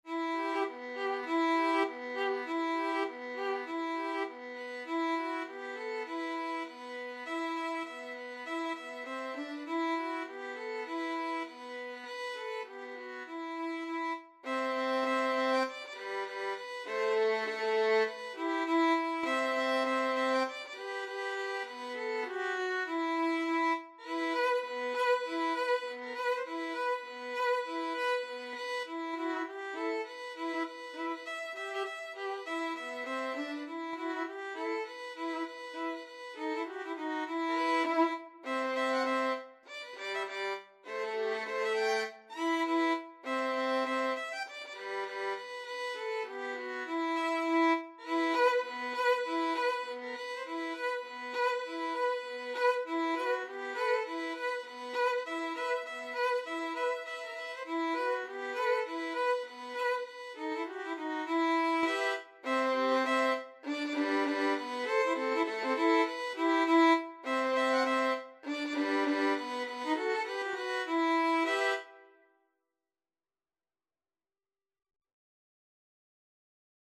Free Sheet music for Violin Duet
Violin 1Violin 2
E minor (Sounding Pitch) (View more E minor Music for Violin Duet )
2/2 (View more 2/2 Music)
Firmly, with a heart of oak! Swung = c.100